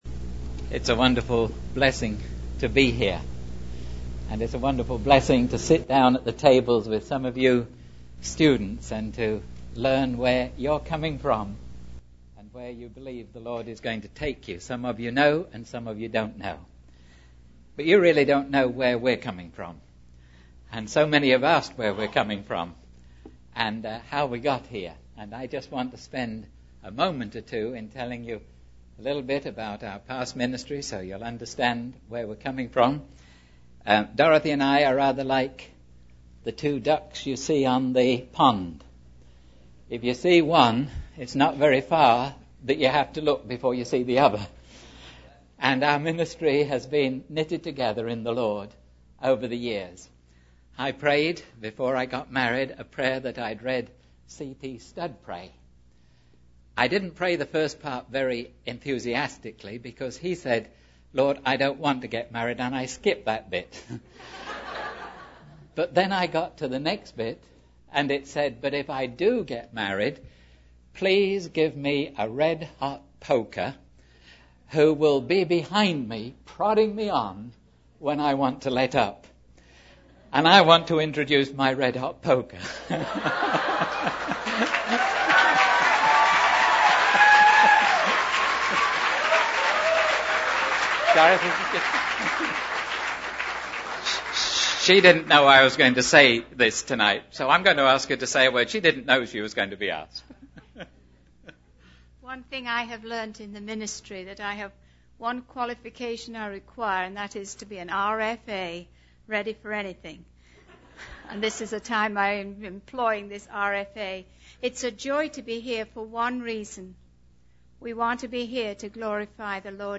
In this sermon, the speaker emphasizes the importance of confessing sins individually rather than in bundles. He highlights the role of leaders in the church, such as priests, ministers, Bible school students, evangelists, and youth leaders, in leading the congregation in repentance. The speaker shares a personal experience of a powerful prayer meeting where the glory of God was felt, leading to people finding salvation.